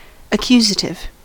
accusative: Wikimedia Commons US English Pronunciations
En-us-accusative.WAV